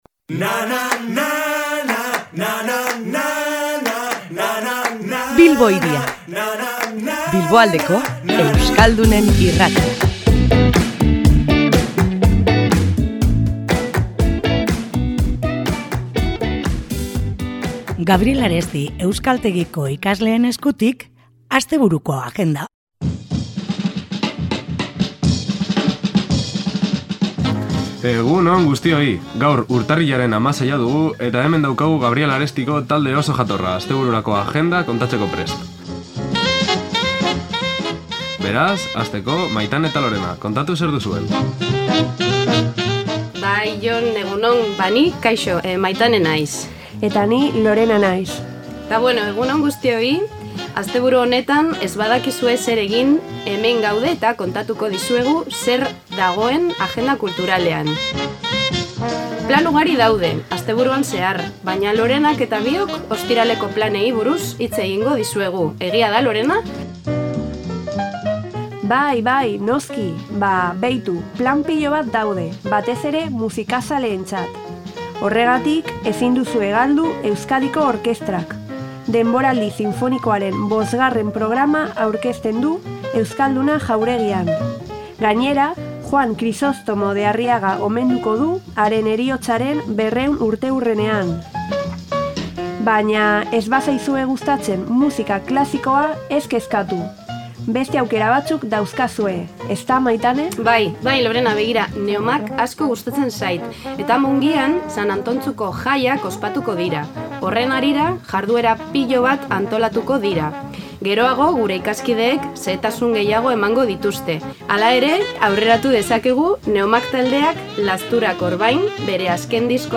Asteburua ate joka dugu, eta ohikoa denez, agendari begirada bat botatzeko unea iritsi da. Gaur, Gabriel Aresti euskaltegiko ikasleak izan ditugu Bilbo Hiria irratian, eta haiek ekarritako proposamenekin astebururako plan erakargarriak mahai gainean jarri ditugu.